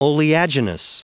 Prononciation du mot oleaginous en anglais (fichier audio)
Prononciation du mot : oleaginous